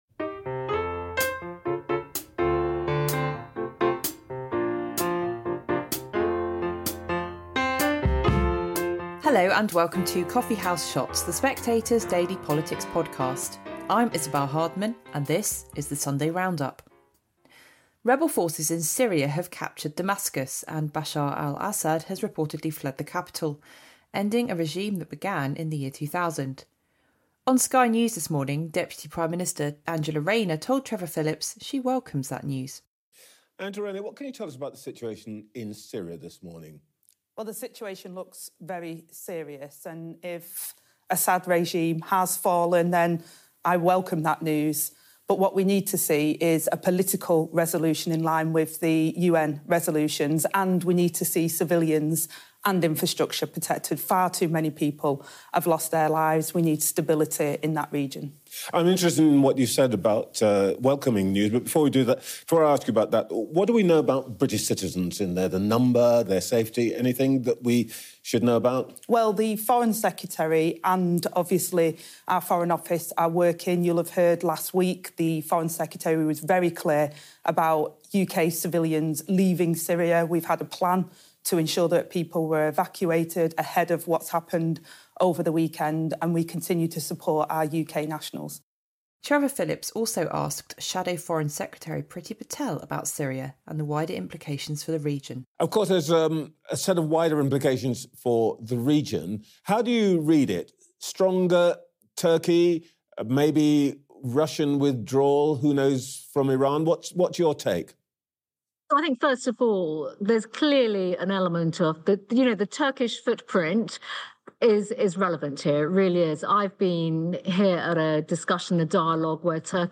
The Assad regime has fallen. We hear from deputy prime minister Angela Rayner on Syria, and her plans to build 1.5 million homes.
Isabel Hardman presents highlights from Sunday morning's political shows.